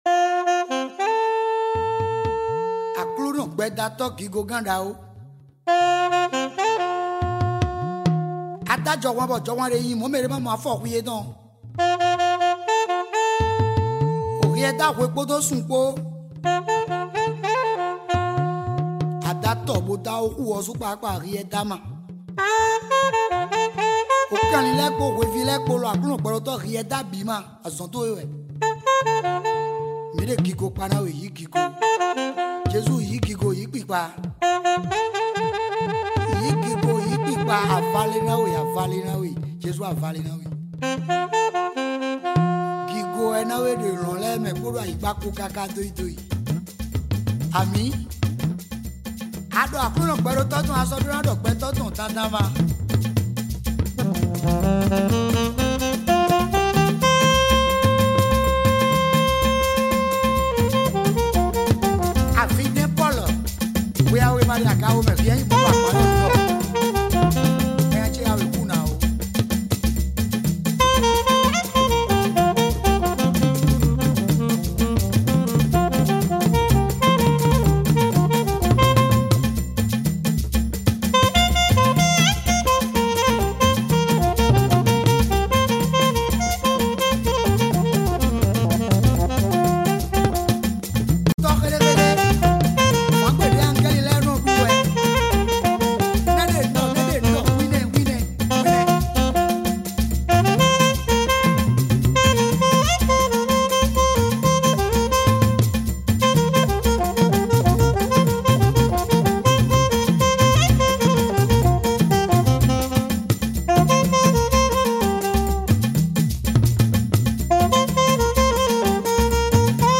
Afro Music